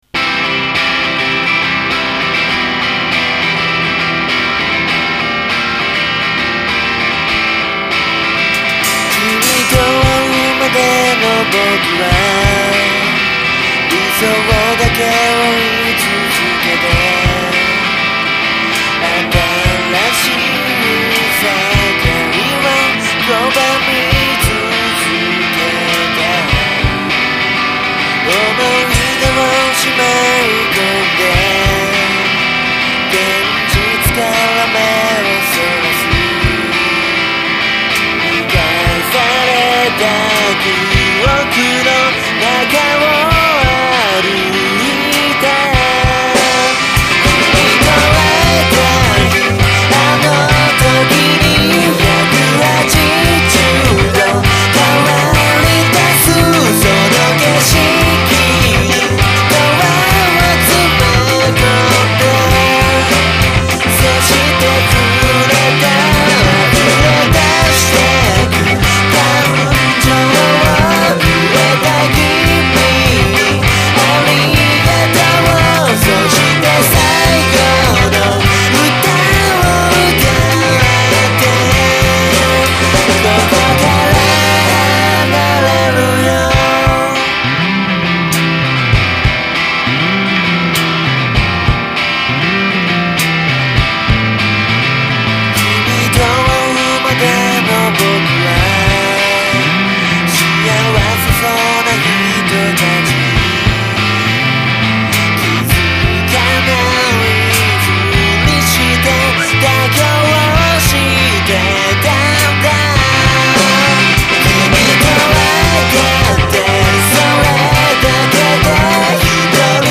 ハモリもあって好きです♡